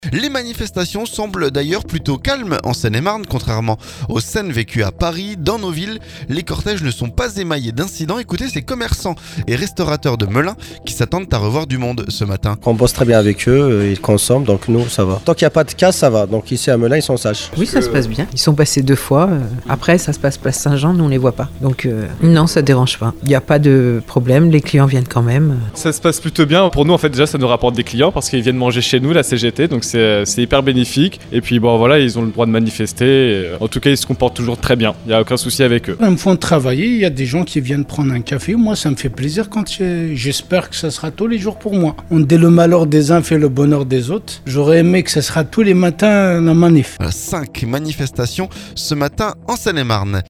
Ecoutez ces commerçants et restaurateurs de Melun, qui s'attendent à revoir du monde ce mardi.